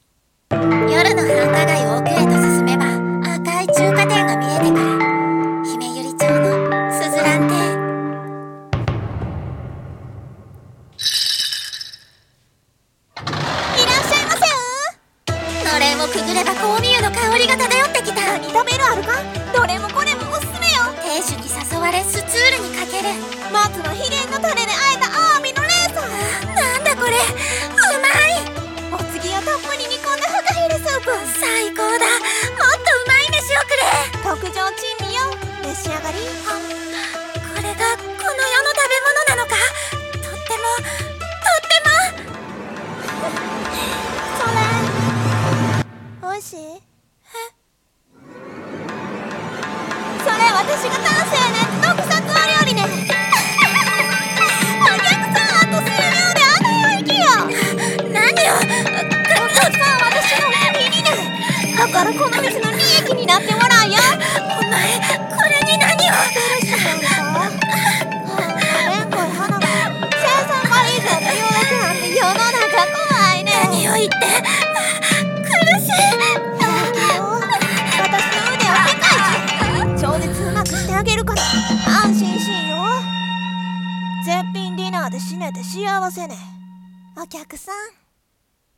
声劇】珍味の鈴蘭中華店